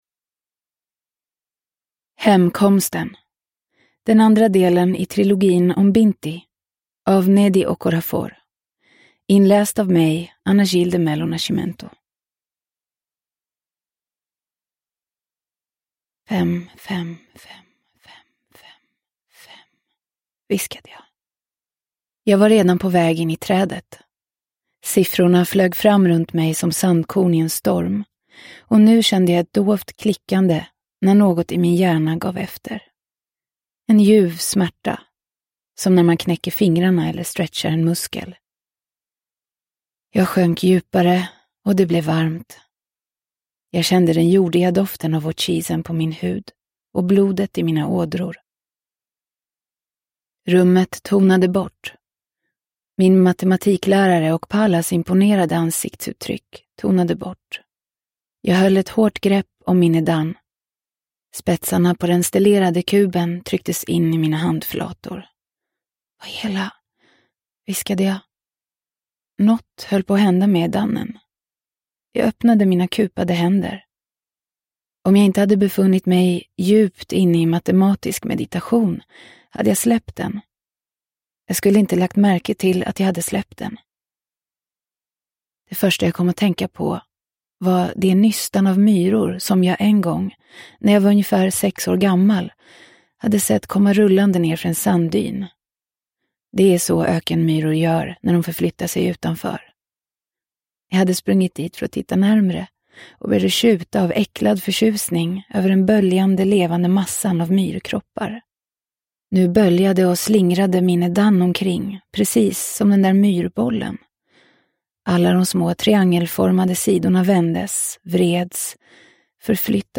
Binti 2: Hemkomsten – Ljudbok – Laddas ner